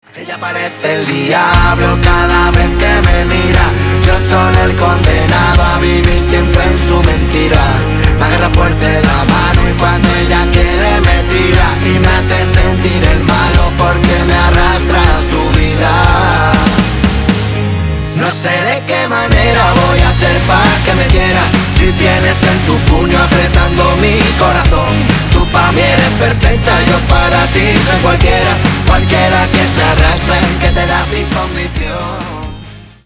Поп Музыка # латинские